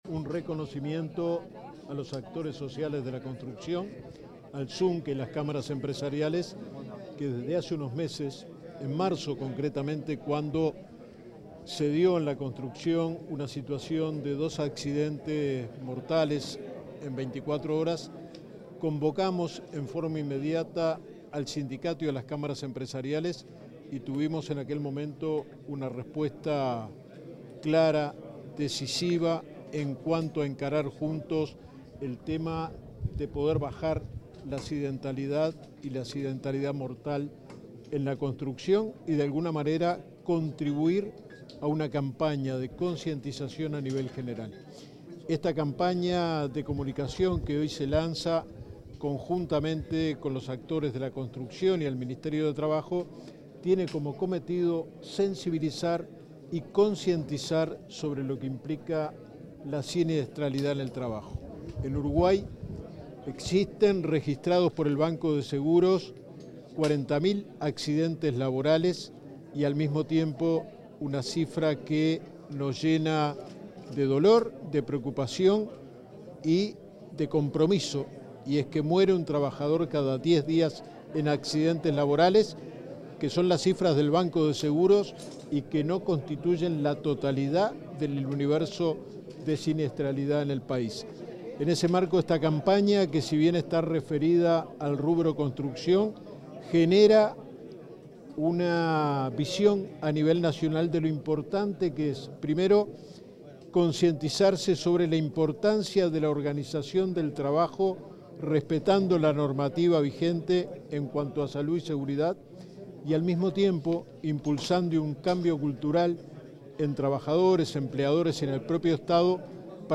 Declaraciones del inspector general de Trabajo, Luis Puig
El inspector general de Trabajo, Luis Puig, efectuó declaraciones a la prensa acerca de la nueva campaña de sensibilización sobre seguridad laboral.